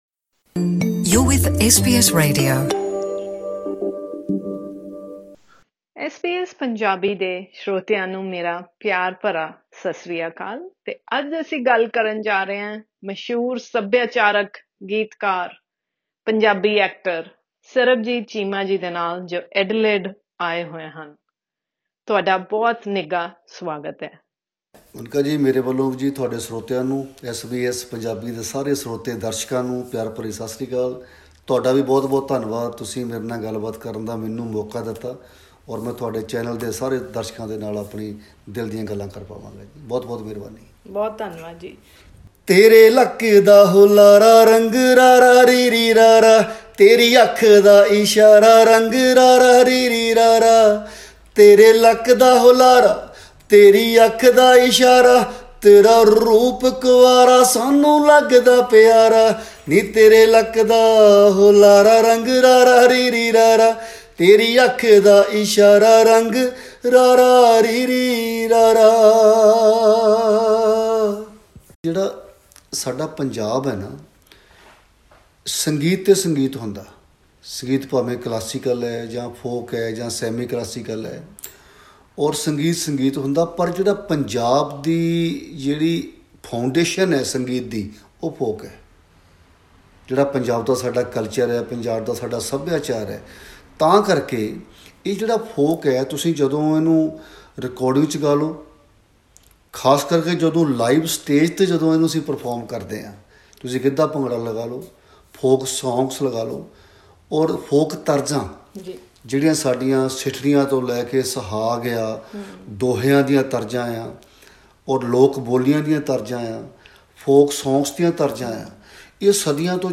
He talks about the role of culture and language in shaping the Punjabi diaspora in an exclusive chat with SBS Punjabi